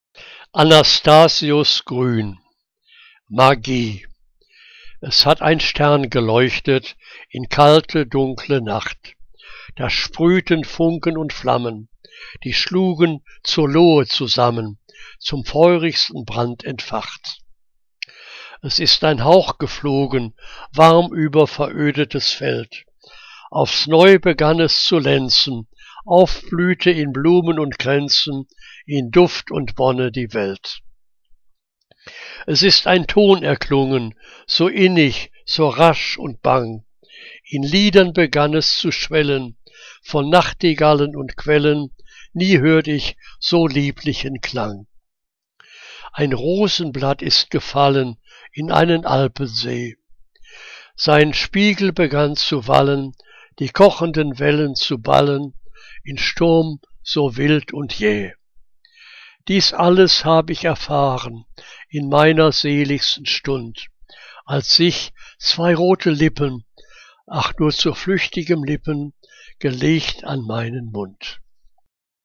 Liebeslyrik deutscher Dichter und Dichterinnen - gesprochen (Anastasius Grün)